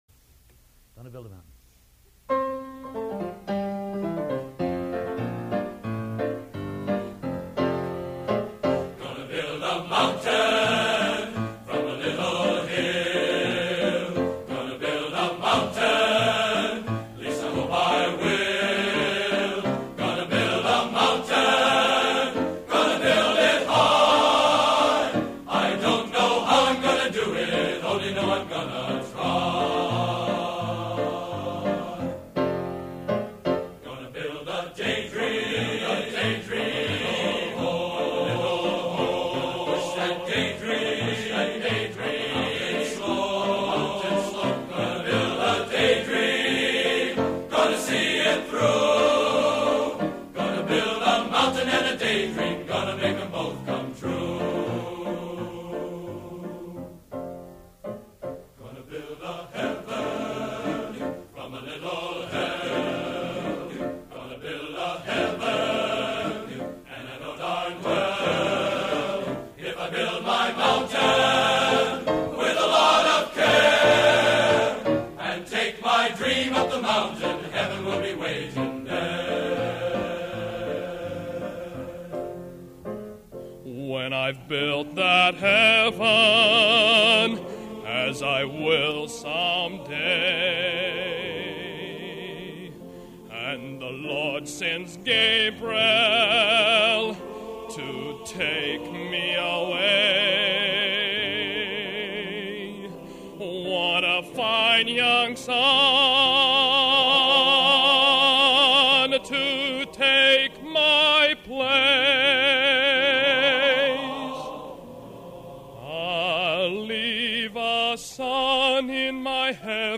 Location: West Lafayette, Indiana
Genre: | Type: End of Season |Solo